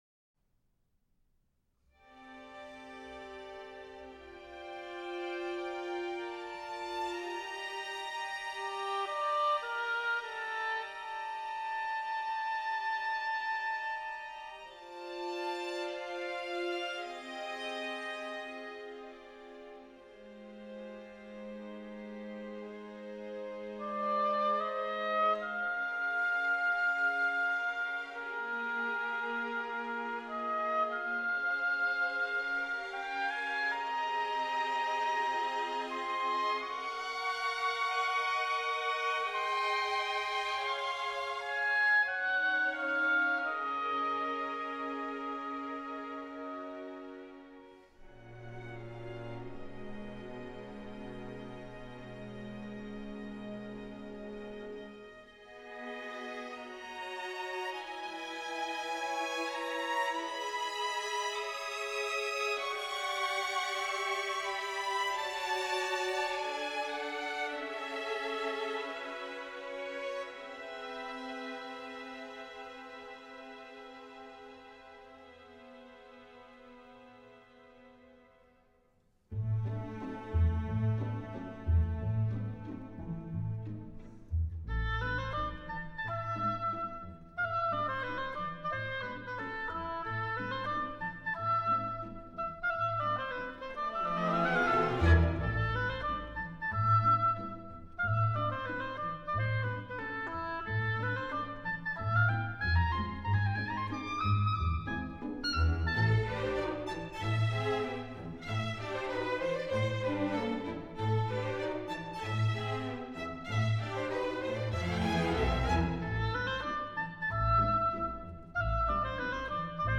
Solo Oboe, String Orchestra